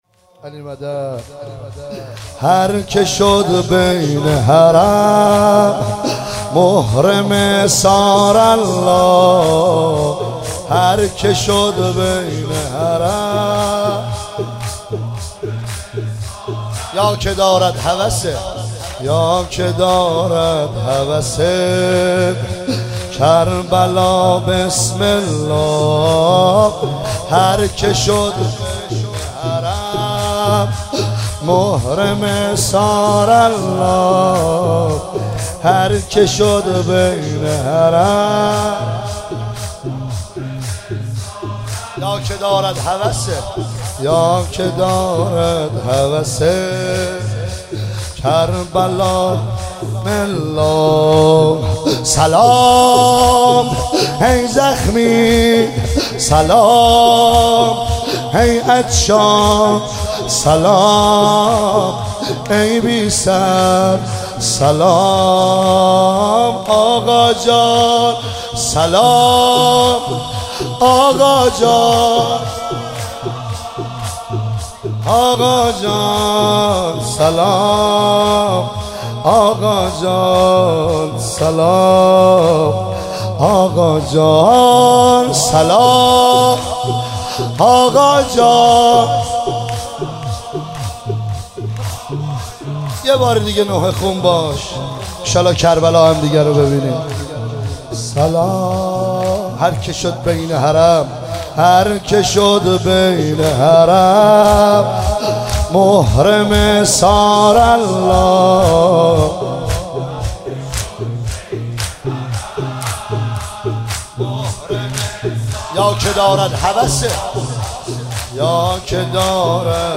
شب 13 محرم 1398 – ساری
زمینه هر که شد بین حرم محرم ثارالله